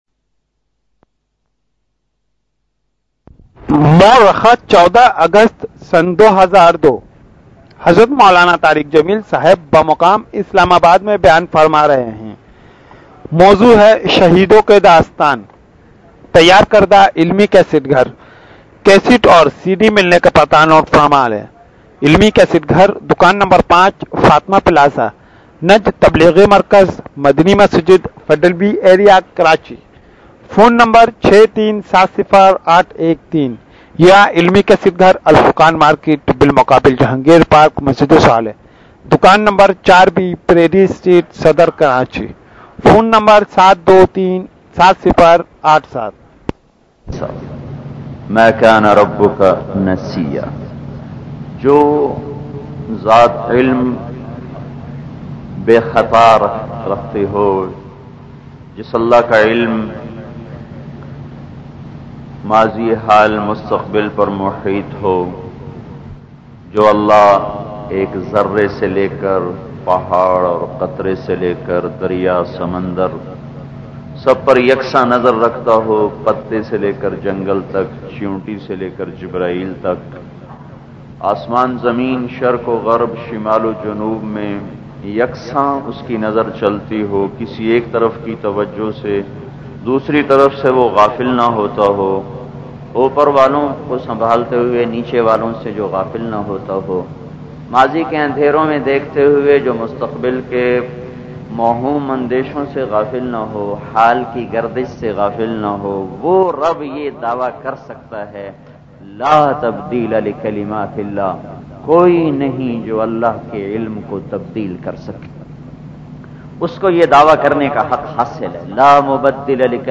Beautiful bayan of moulana tariq jameel mp3